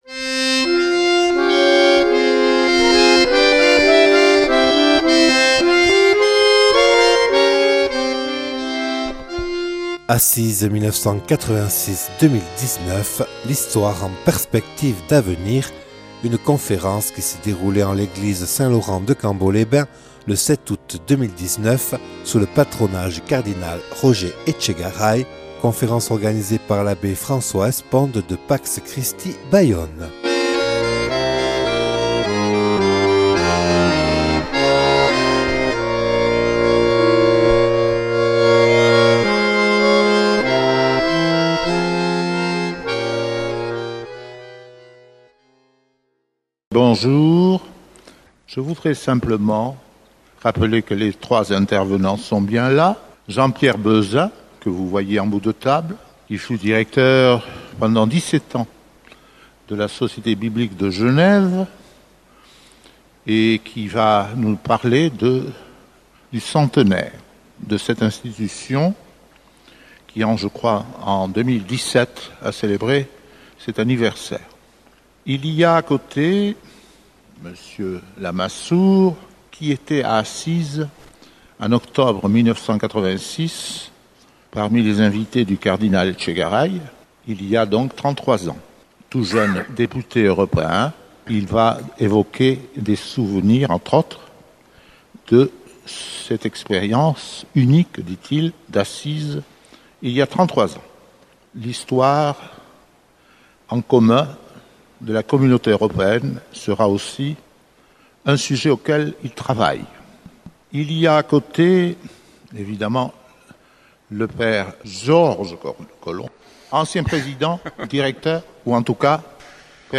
(Enregistré le 07/08/2019 en l’église de Cambo lors de la rencontre organisée par Pax Christi sous le patronage du Cardinal Roger Etchegaray).